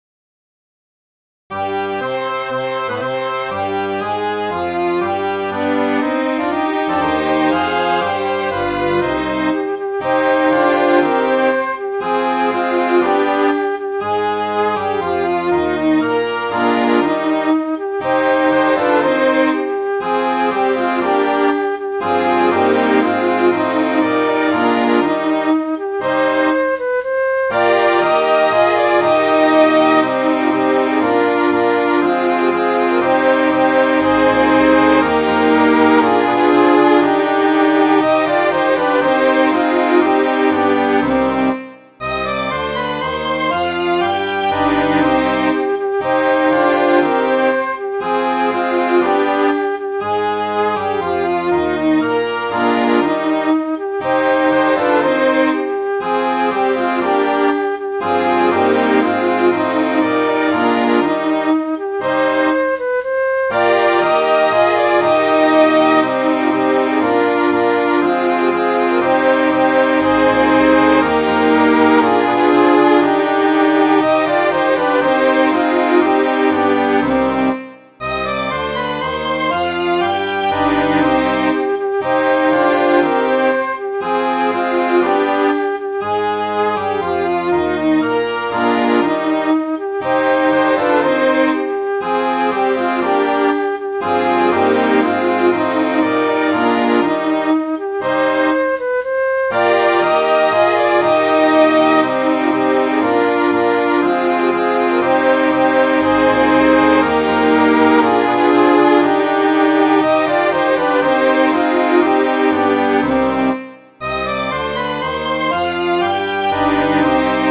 Midi File, Lyrics and Information to Down Among the Dead Men